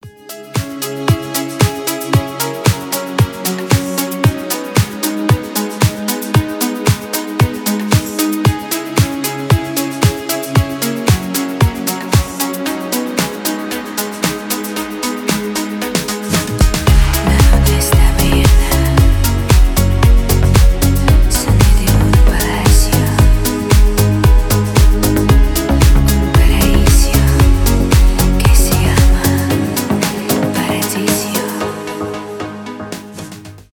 мелодичные
танцевальные
deep house